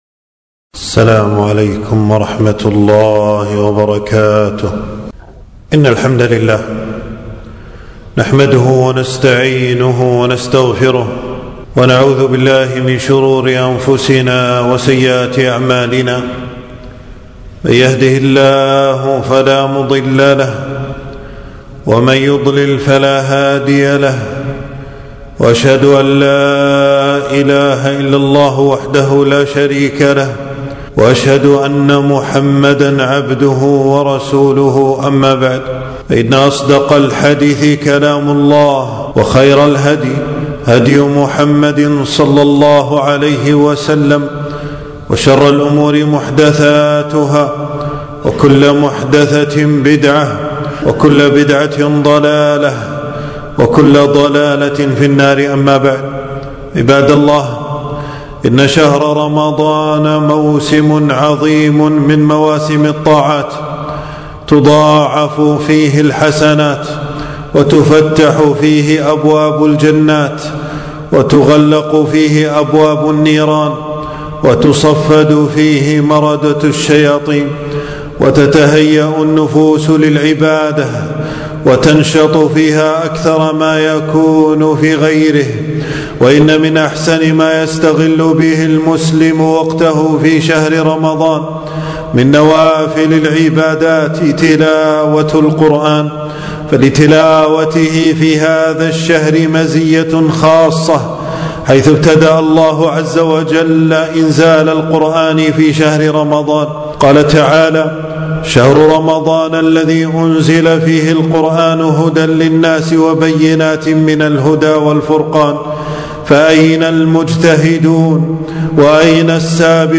خطبة - فضل القرآن والقيام في رمضان